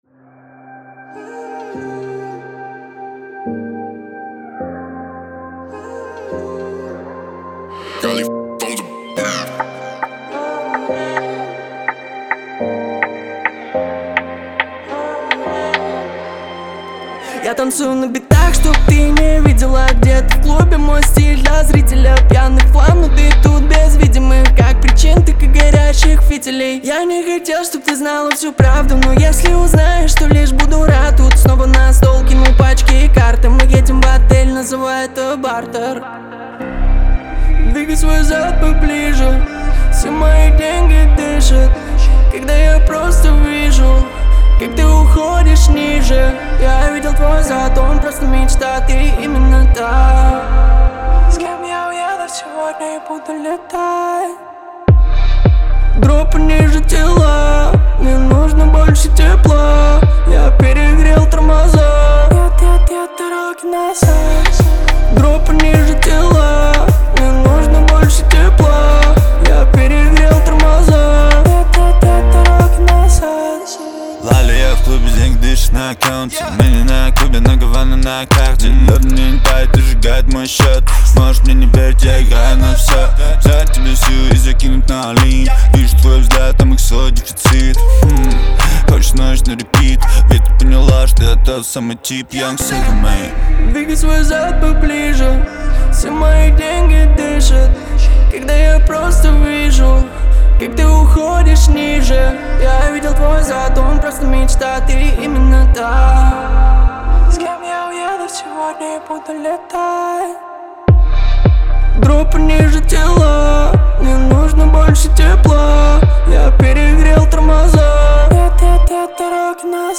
это яркая композиция в жанре хип-хоп